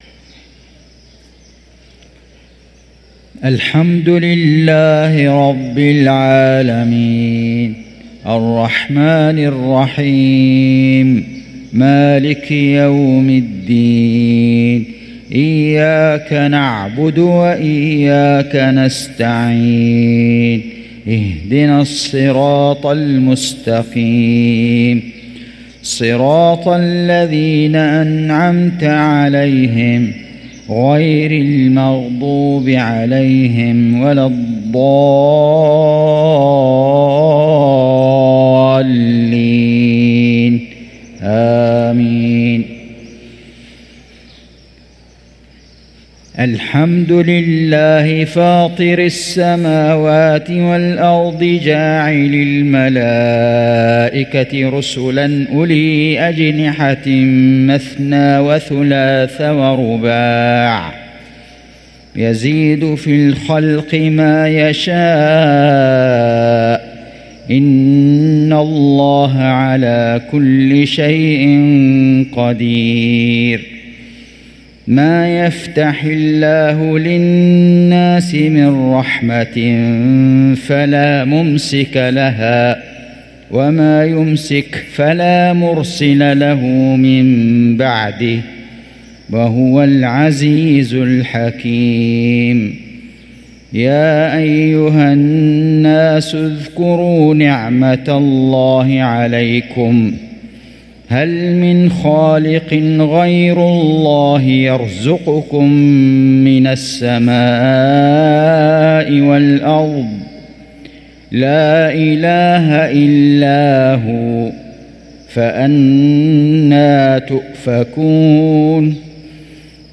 صلاة المغرب للقارئ فيصل غزاوي 4 رجب 1445 هـ
تِلَاوَات الْحَرَمَيْن .